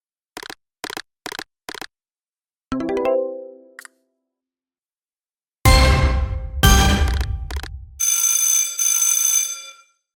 16. Звук игрового автомата, выпадения бонуса